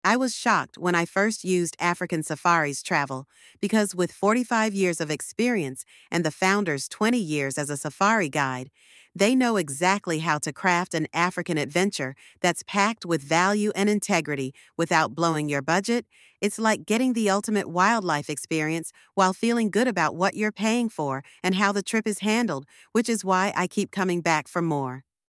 85342-voiceover.mp3